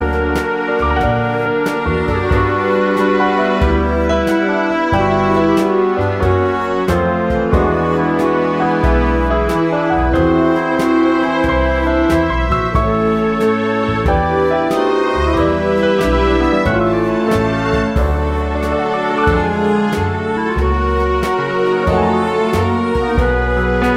With Choir Oldies (Female) 2:58 Buy £1.50